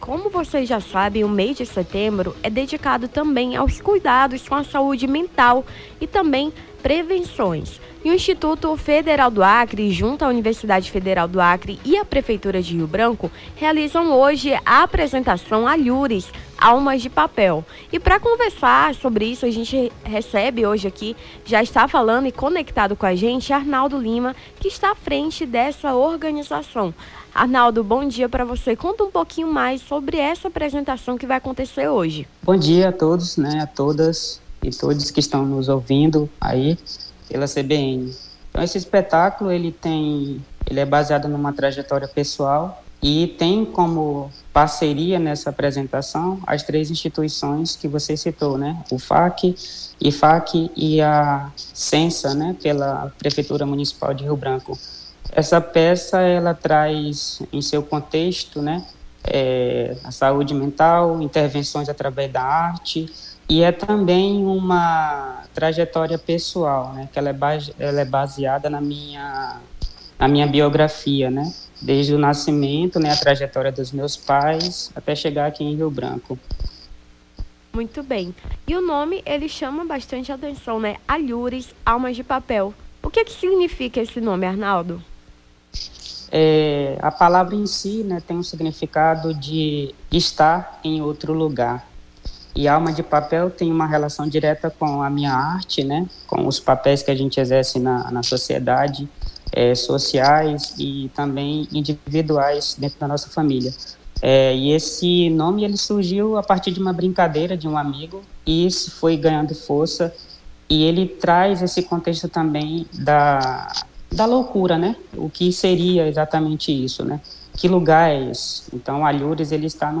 Nome do Artista - CENSURA - ENTREVISTA ALHURES ALMA DE PAPEL (17-09-24).mp3